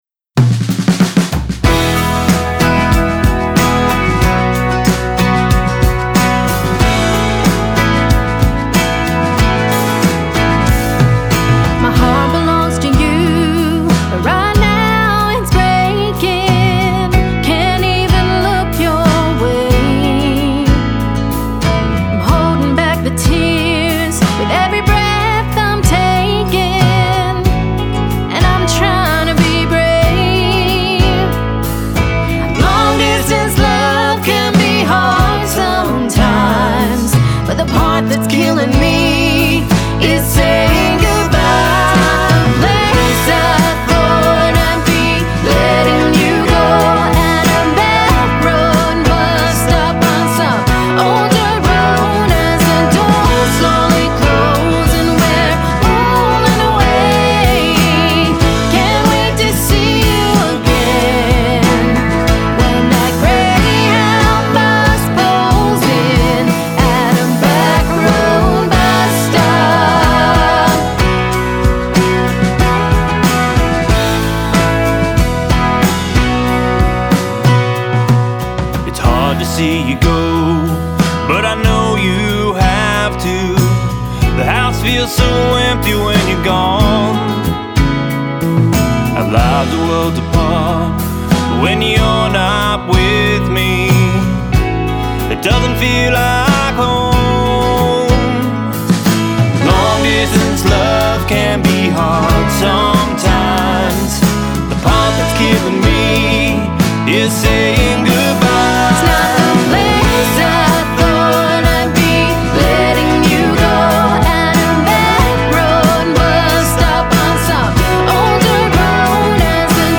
contemporary country duo
sweet soulful vocals
rocky edge